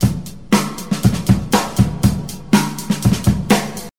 • 120 Bpm Drum Loop Sample E Key.wav
Free drum loop sample - kick tuned to the E note. Loudest frequency: 1280Hz
120-bpm-drum-loop-sample-e-key-vOZ.wav